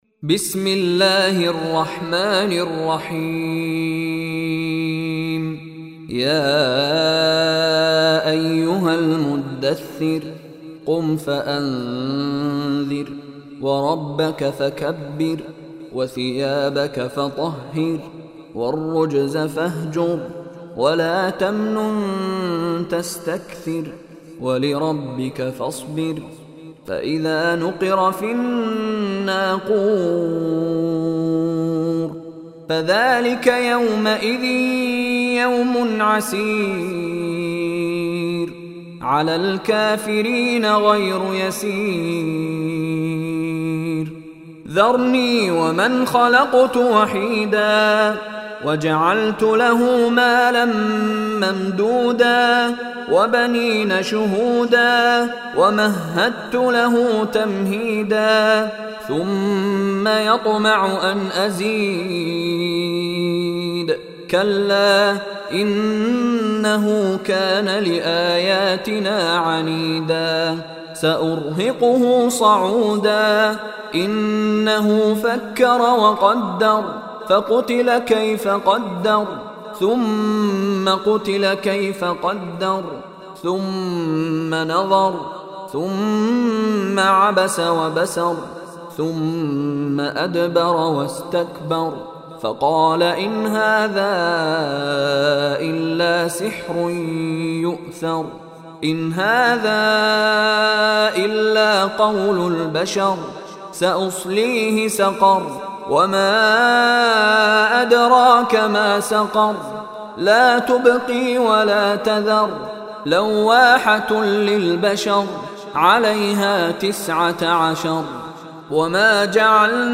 Surah Mudassir Beautifull Recitation MP3 Download By Mishary Rashid Alafasy in best audio quality.